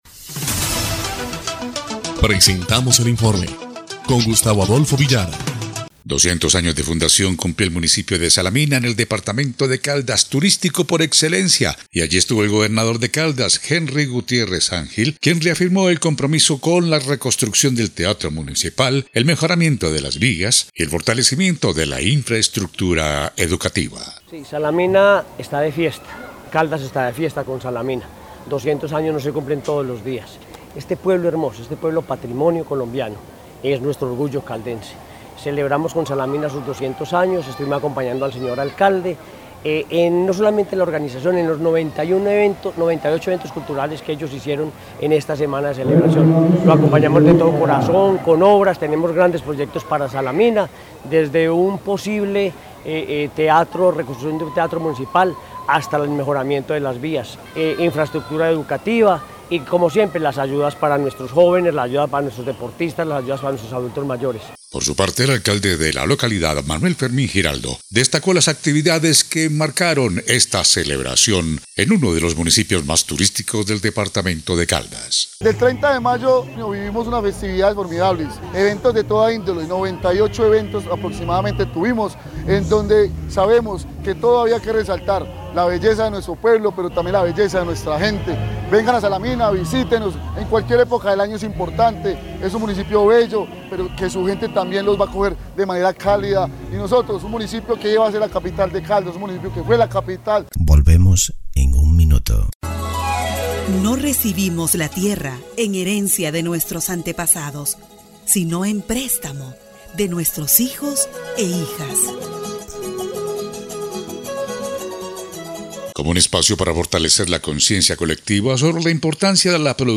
EL INFORME 4° Clip de Noticias del 10 de junio de 2025